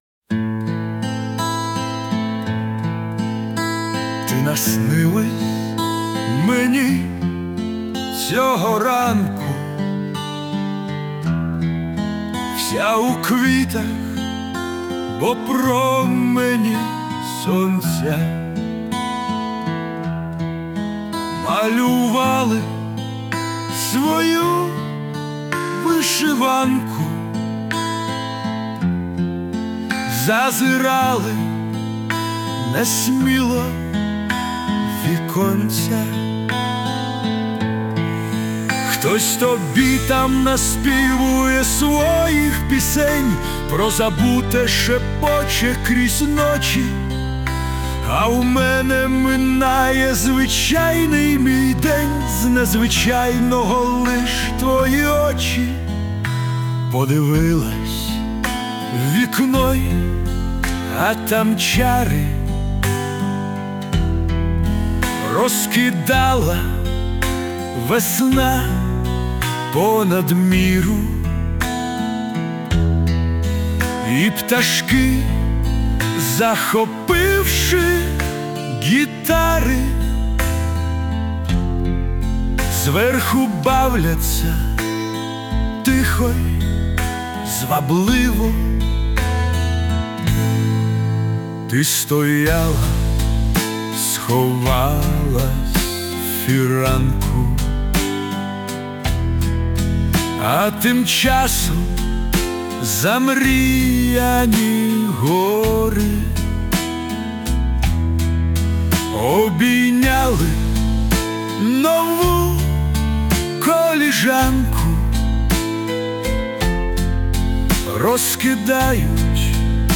Варіант пісні
Музичне прочитання з допомогою ШІ
СТИЛЬОВІ ЖАНРИ: Ліричний